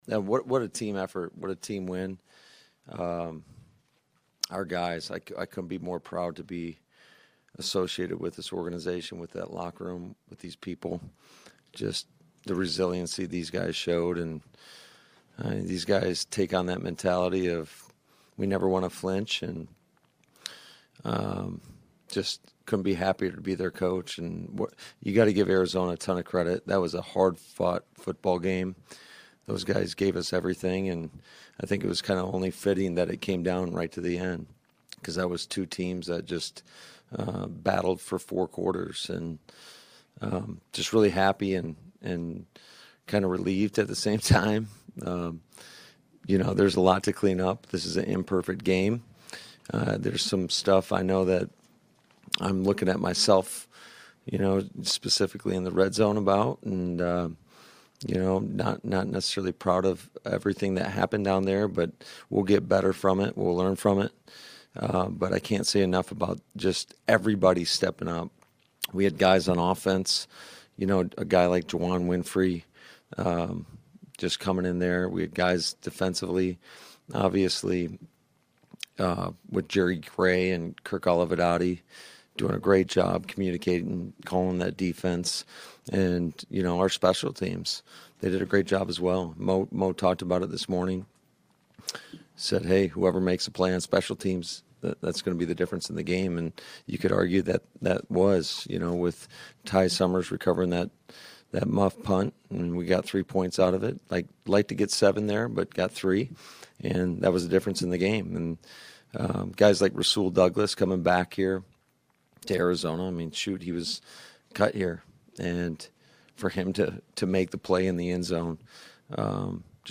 LaFleur covered it all to begin his post-game press conference.